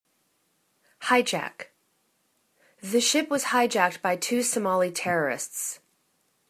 hi.jack     /'hidjak/    v